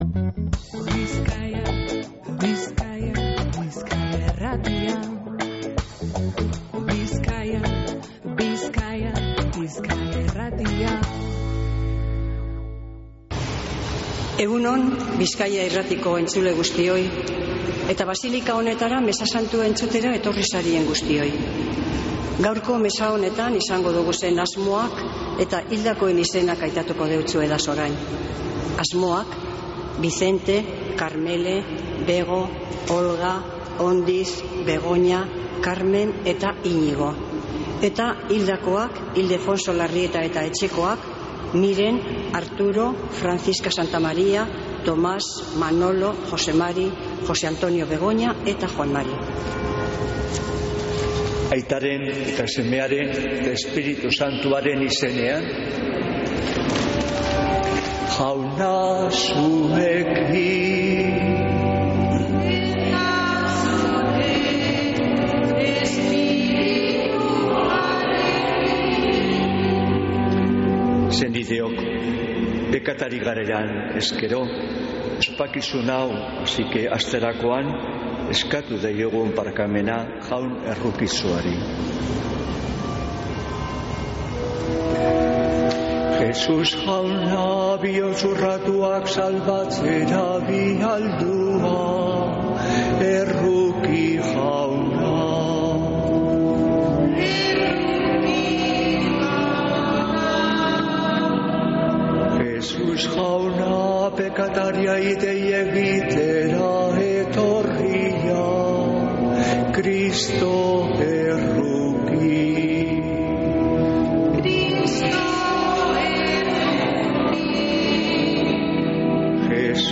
Mezea Begoñako basilikatik | Bizkaia Irratia